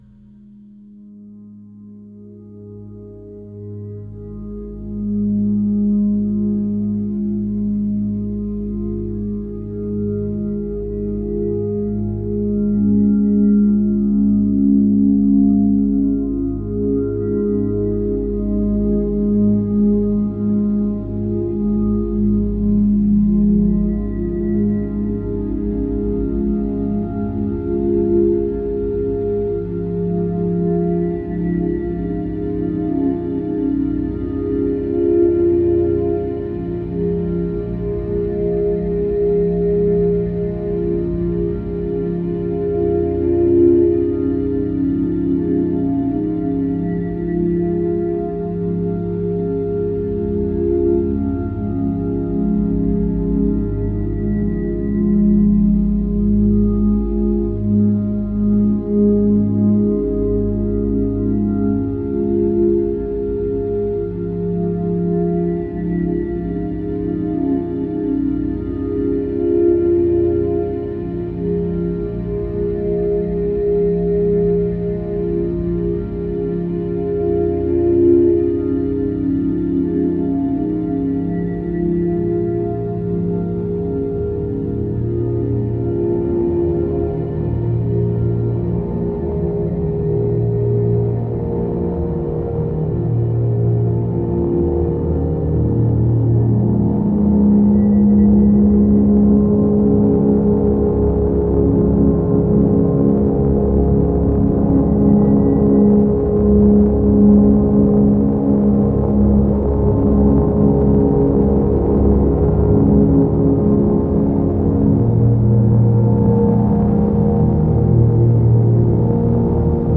artiste sonore